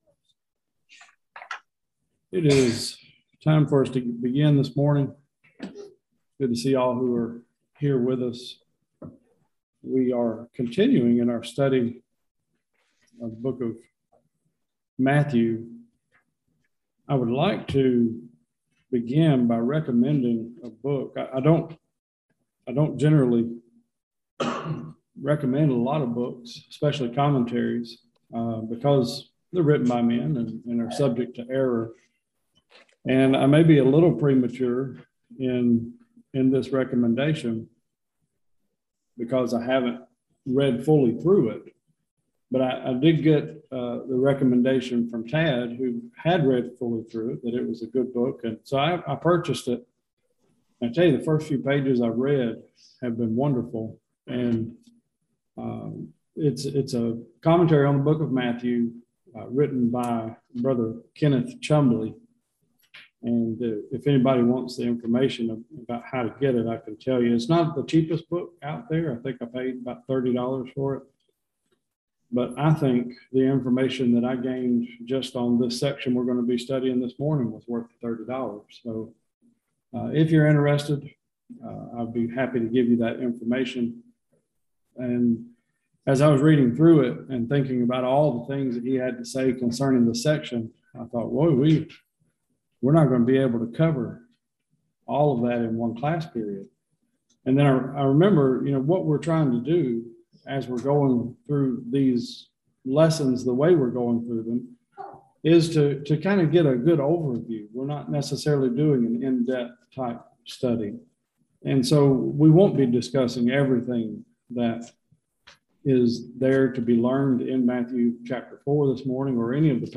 Matthew 4:1-25 Service Type: Bible Classes Jesus is led by the Spirit into the wilderness to be tempted by the devil.